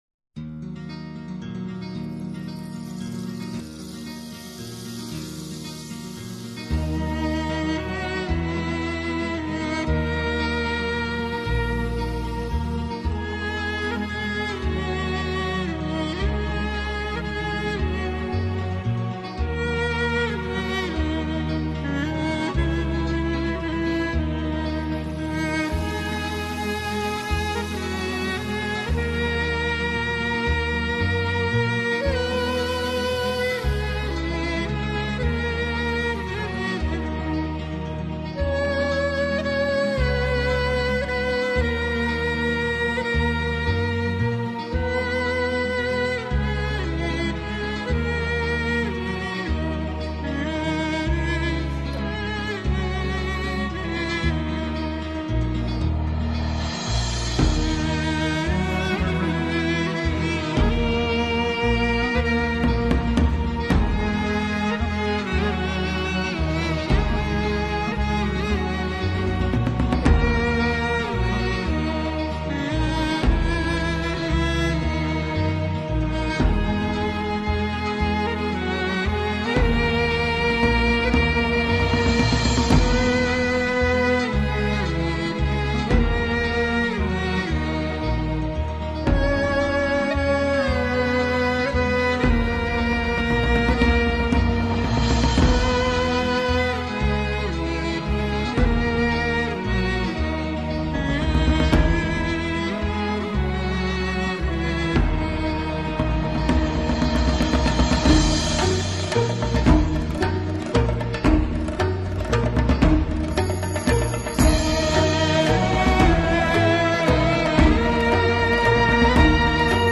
ببخشید من یک قطعه موسیقی بیکلام دارم، قبلا فکر کنم توی راهیان نور شنیدم.
بی کلام_1.mp3